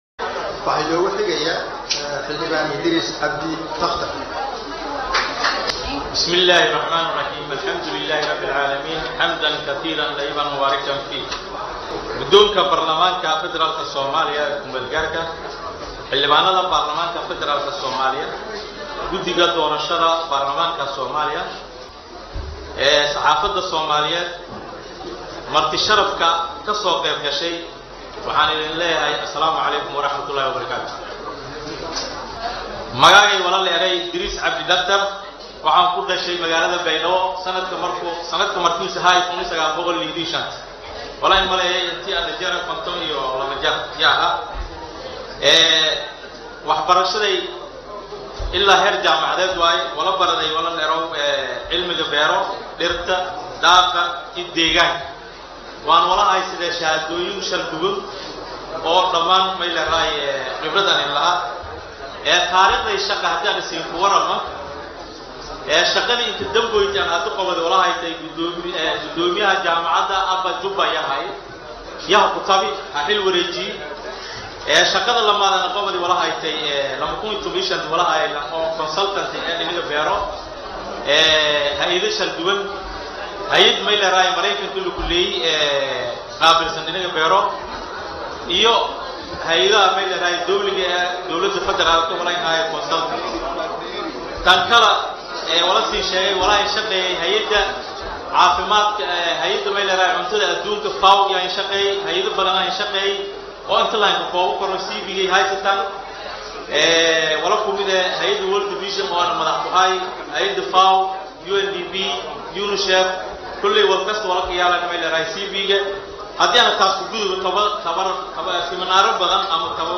Baydhabo(INO)-Xildhibaan Dr. Idiris Cabdi takhtar ayaa noqodey Xildhibaanklii ugu Horeeeyey oo Af Maay K u Jeediyey Gollaha Shacabka Soomaaliya
IDRISS-TAKTAR-OO-NOQDEY-XILDHIBAANKII-UGU-HOREEYEY-OO-AF-MAAY-KU-JEEDIYO-KHUDBAD-GOLAHA-BAARLAMAANKA.mp3